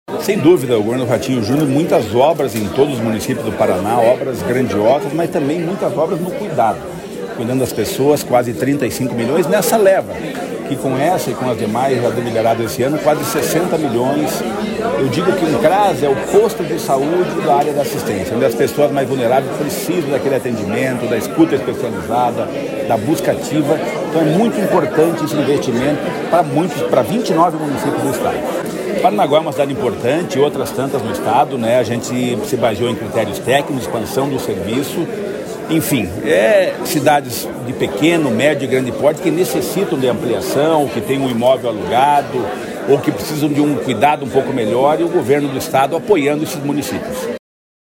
Sonora do secretário do Desenvolvimento Social e Família, Rogério Carboni, sobre o repasse de R$ 34,8 milhões para ampliar assistência social em 29 municípios